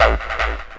tekTTE63020acid-A.wav